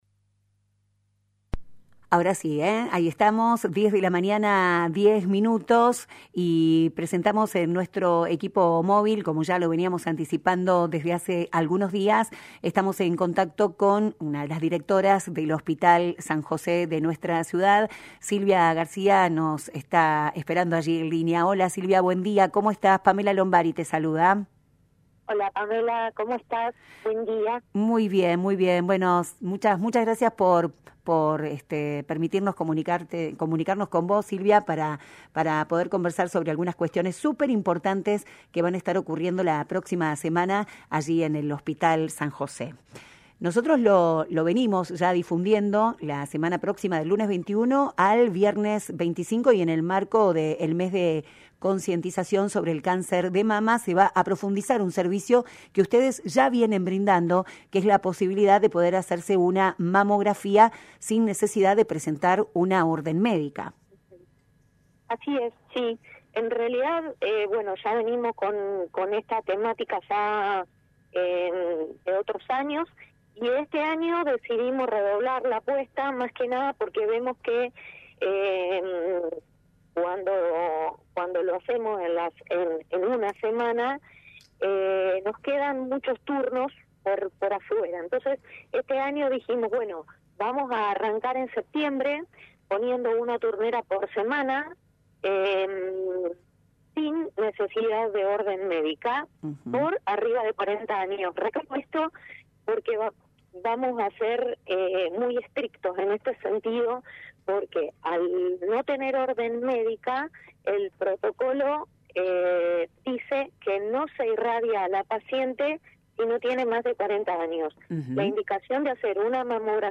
El Hospital San José de Pergamino estará llevando a cabo una semana especial de mamografías sin necesidad de orden médica del 21 al 25 de octubre. Esta iniciativa, que busca facilitar el acceso a este estudio fundamental para la detección temprana del cáncer de mama, está dirigida a mujeres mayores de 40 años. En entrevista